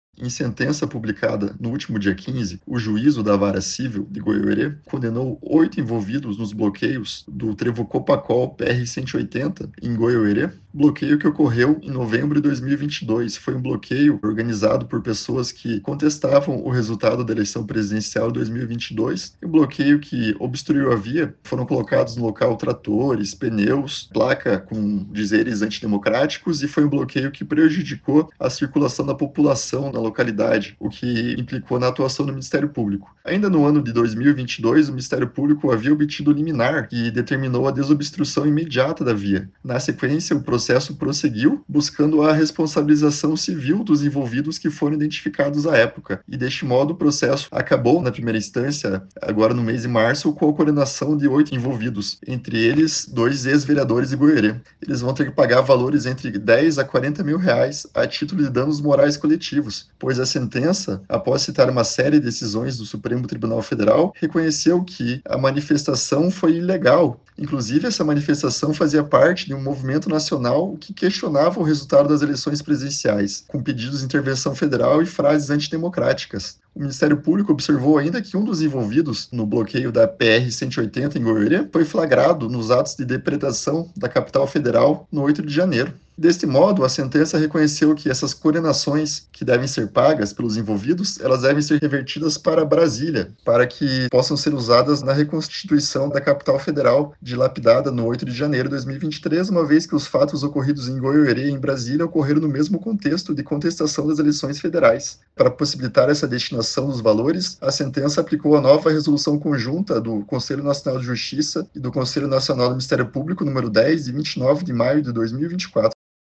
Ouça o que disse o promotor.